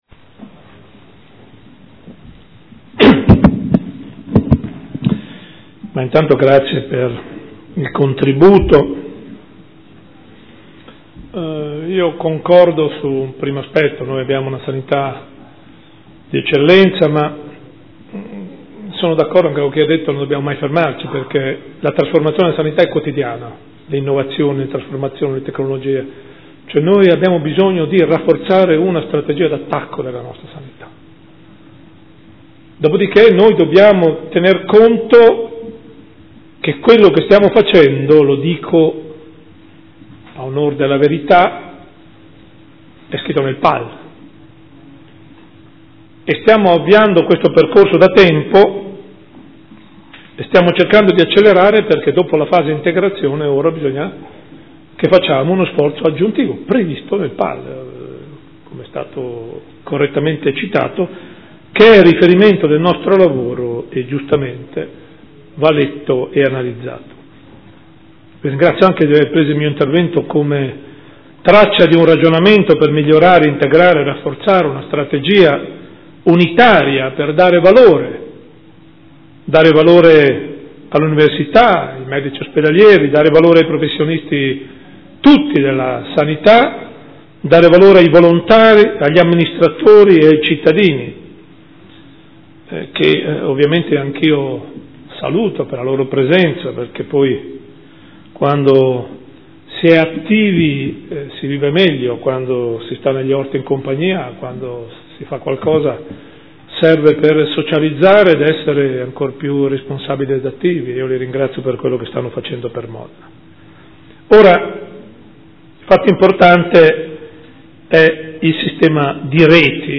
Gian Carlo Muzzarelli — Sito Audio Consiglio Comunale
Seduta del 23/07/2015 Dibattito sulla Sanità